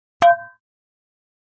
Frying Pan Hit Version 3 - Light